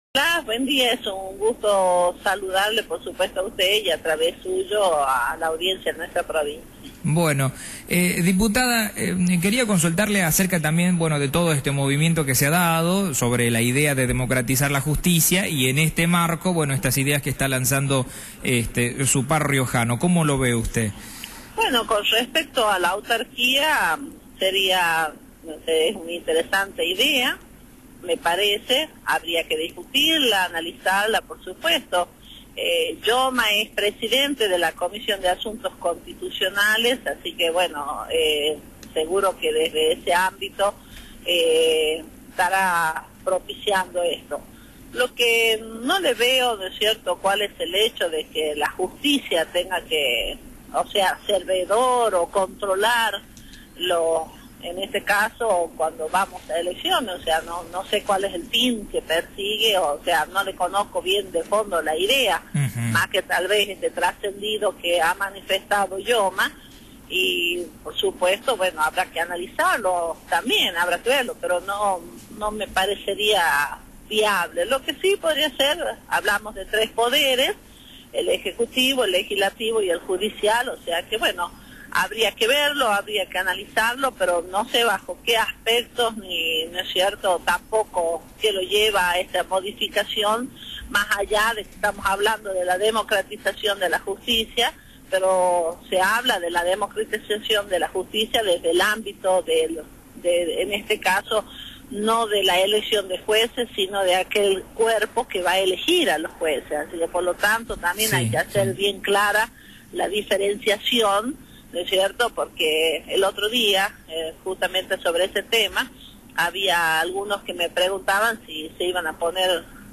Griselda Herrera, diputada nacional, por Radio Fénix